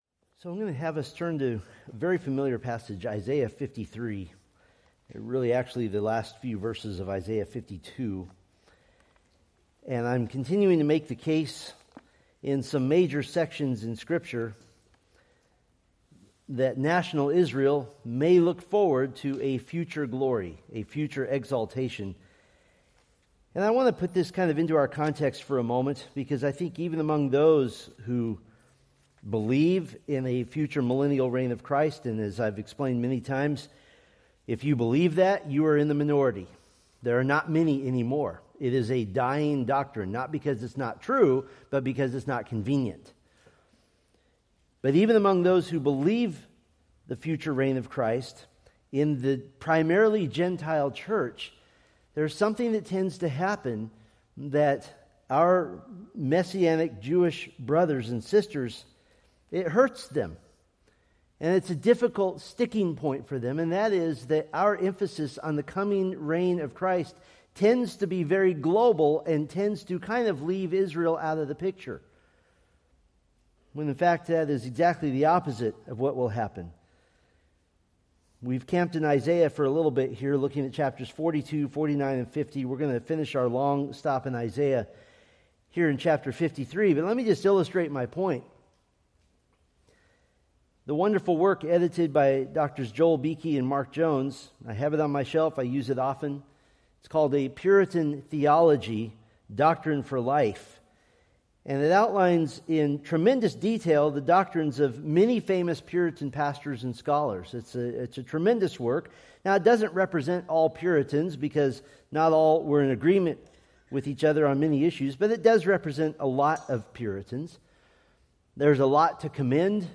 Preached December 7, 2025 from Selected Scriptures